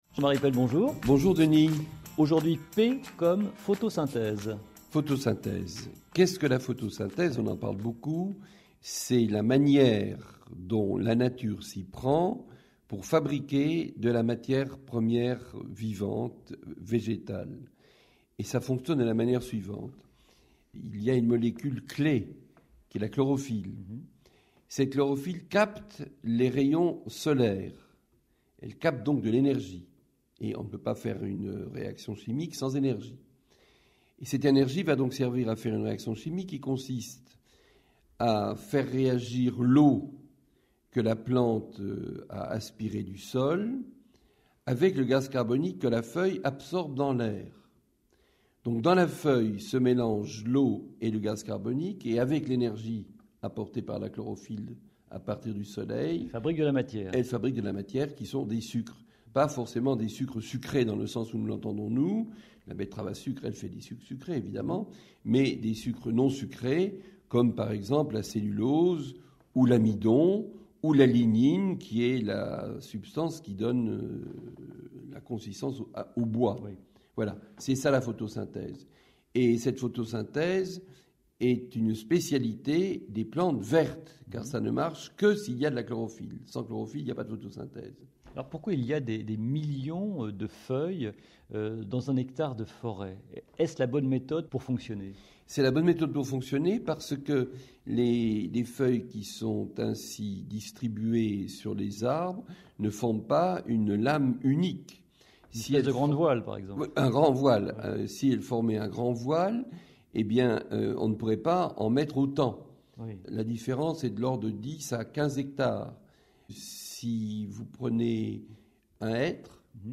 Interview sur RMC le 10 Juin 2006